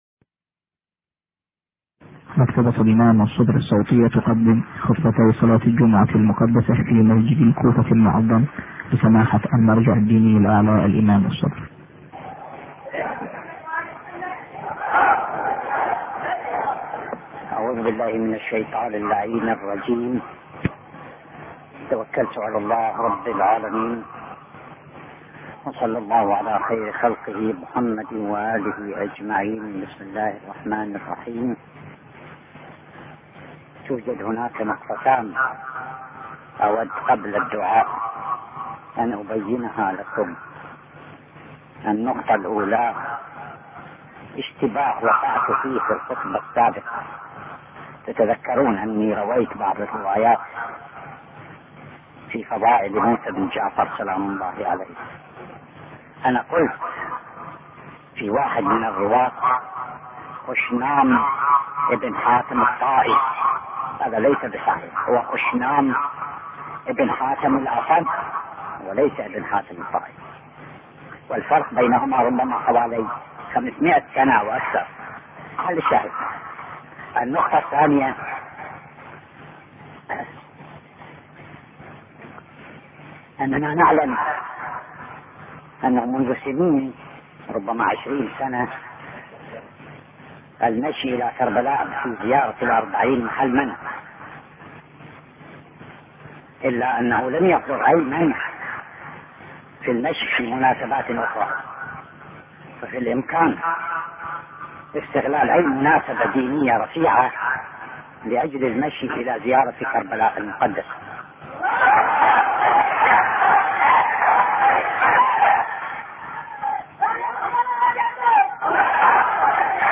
٣٢ : خطبتي صلاة الجمعة الثانية والثلاثون للإستماع والتحميل
خطبتي صلاة الجمعة الثانية والثلاثون لسماحة اية الله العظمى السيد الشهيد محمد محمد صادق الصدر والتي القيت في مسجد الكوفة المعظم للتحميل من المرفقات.